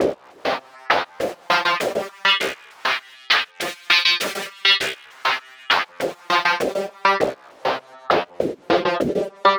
tx_synth_100_sidewind_CFG.wav